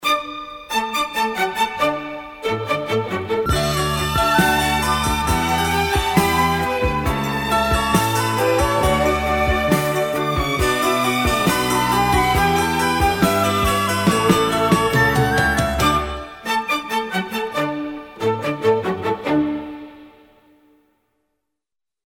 • Качество: 192, Stereo
Красивая классическая композиция